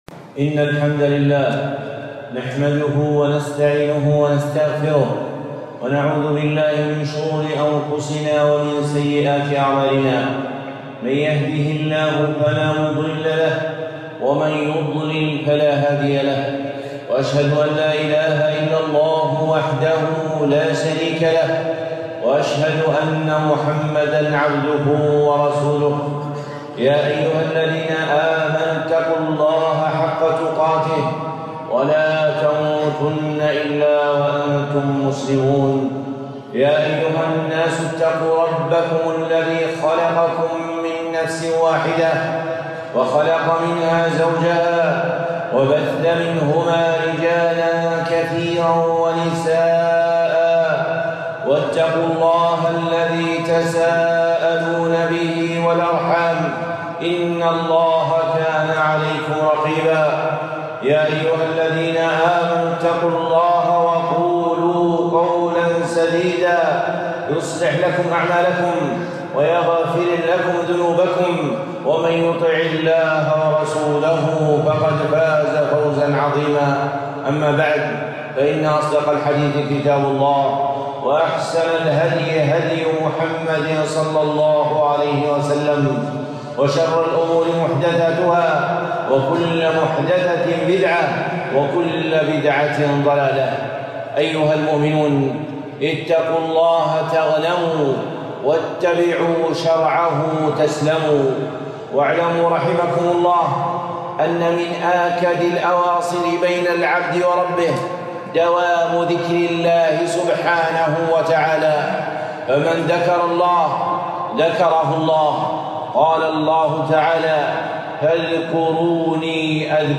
خطبة قيمة - الكلمات الأربع المحبوبات